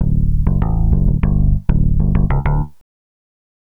Swinging 60s 3 Bass-D.wav